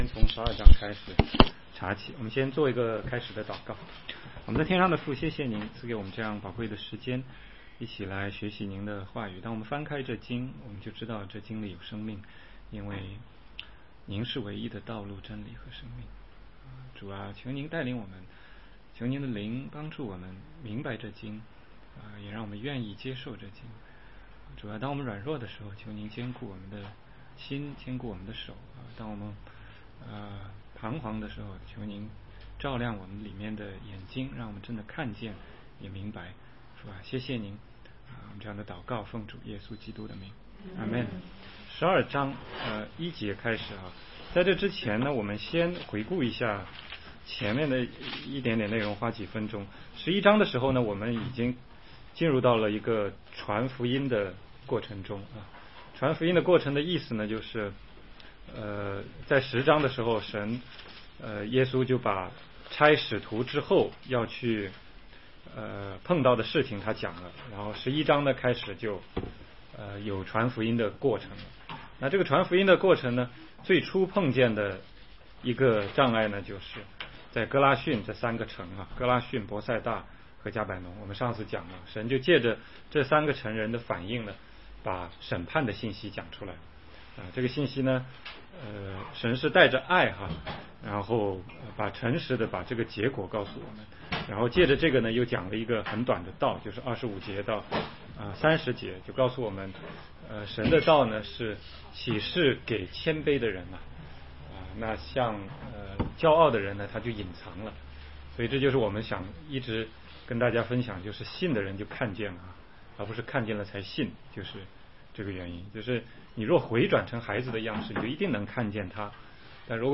16街讲道录音 - 马太福音12章1-8节